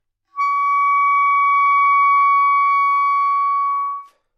单簧管单音 " 单簧管 Csharp6
Tag: 好声音 单注 单簧管 多重采样 纽曼-U87 Csharp6